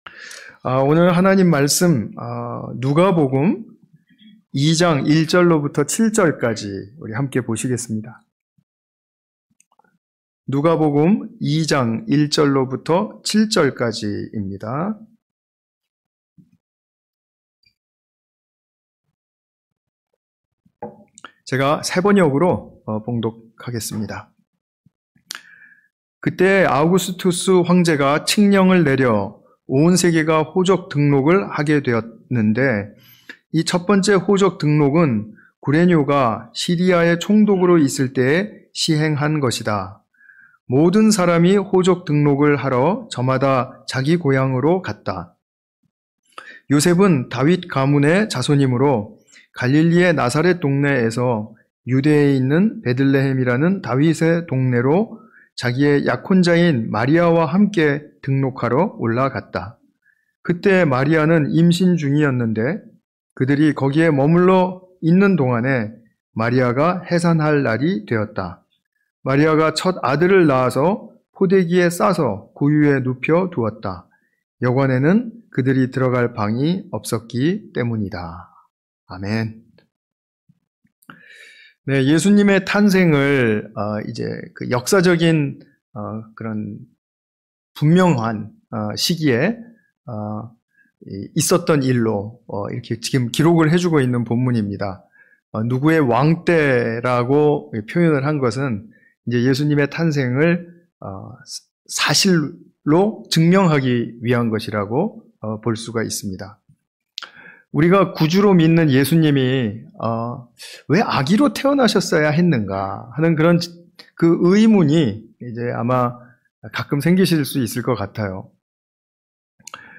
아기 탄생으로부터 십자가까지 (성탄이브예배)